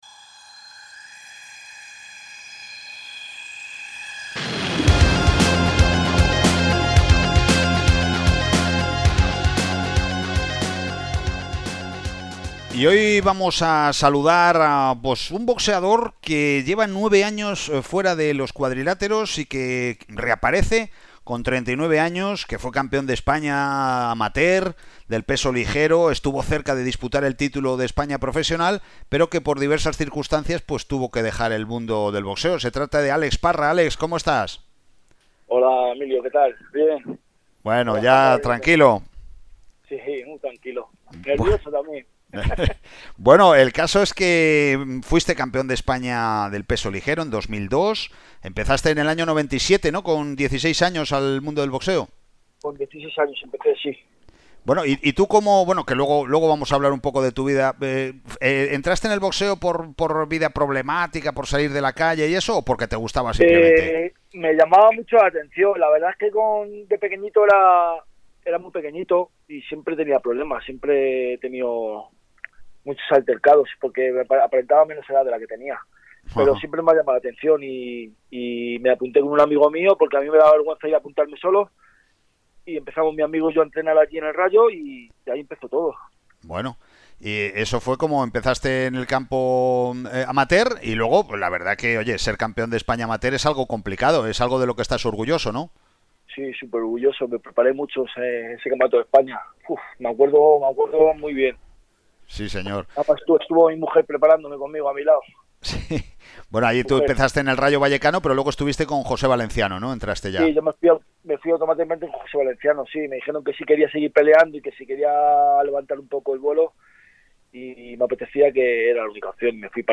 Aquí podemos escuchar la entrevista completa (Primera Parte)